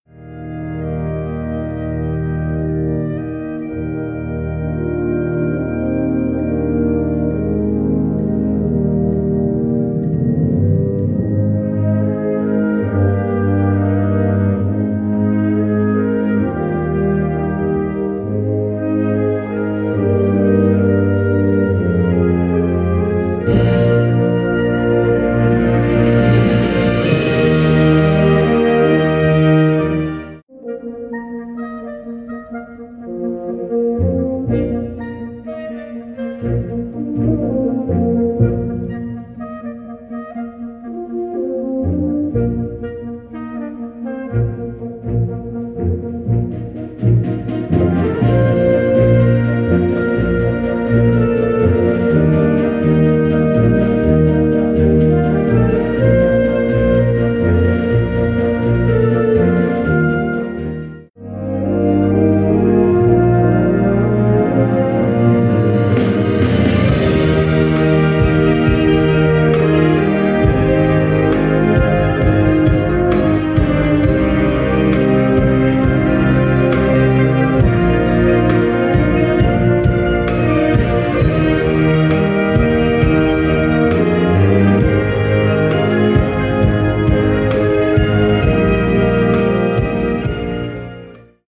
Muestra de música
Categoría Banda sinfónica/brass band
Subcategoría Música de concierto / Música sinfónica
Instrumentación/orquestación Ha (banda de música)